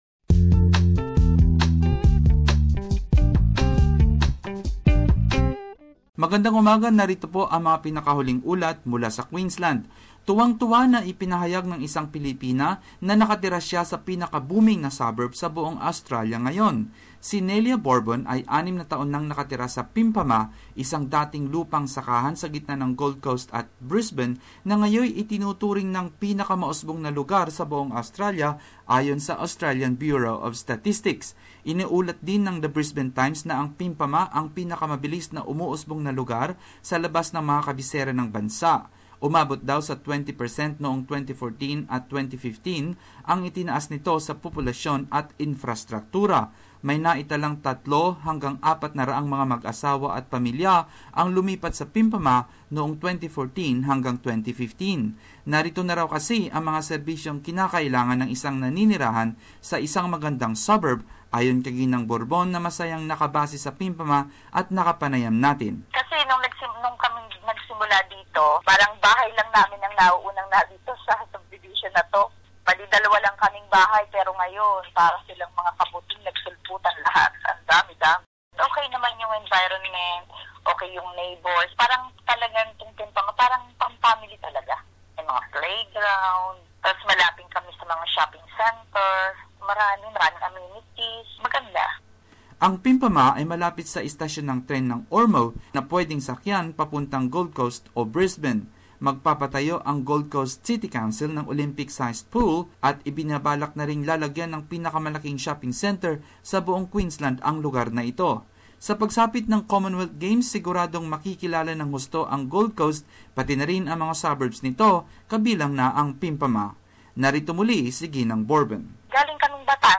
Queensland News.